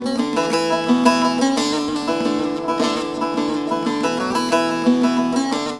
SAZ 06.AIF.wav